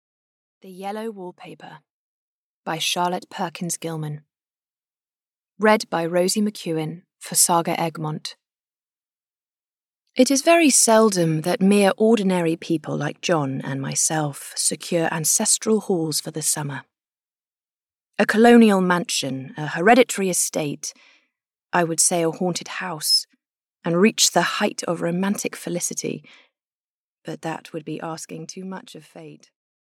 Audio knihaThe Yellow Wallpaper (Premium) (EN)
Ukázka z knihy
the-yellow-wallpaper-premium-en-audiokniha